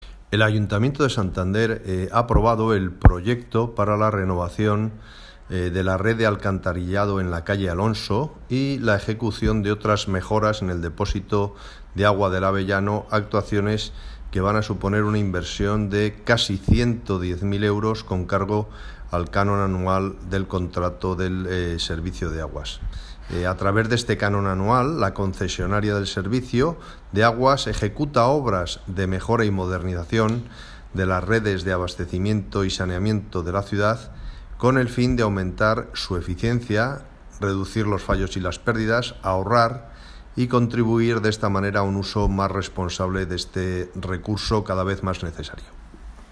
Audio de José Ignacio Quirós: